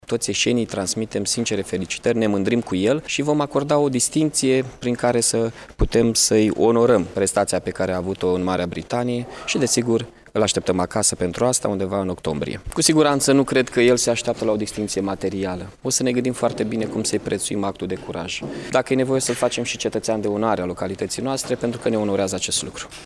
Edilul a declarat într-o conferință de presă că îi transmite sincere felicitări și că îi va acorda o distincție: